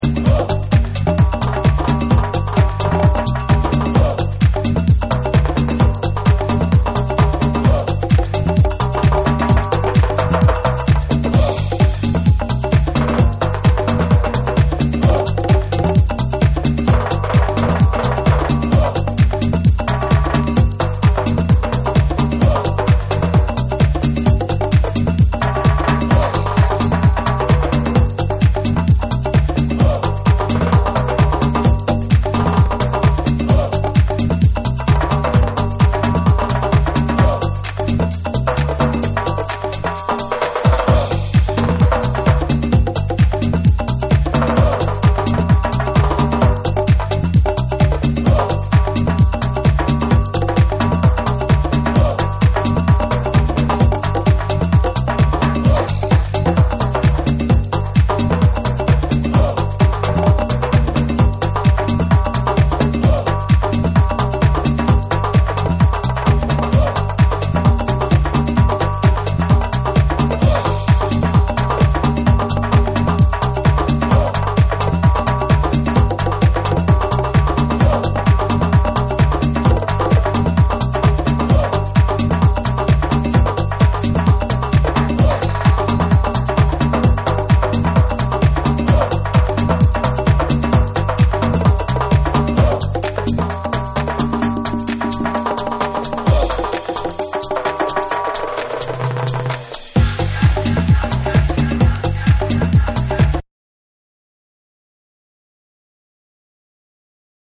超絶なトライバル・ビート上をネイティヴな密林系アフリカン・ヴォイスがレイヤーされたプリミティヴ・ダンス
TECHNO & HOUSE / TRIBAL MADNESS